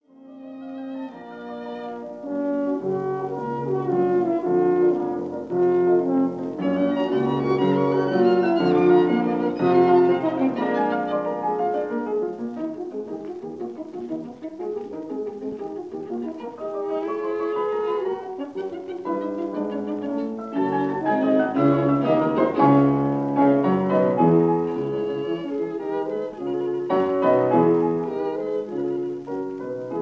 chamber piece
natural horn, violin, and piano
This is the Scherzo (allegro)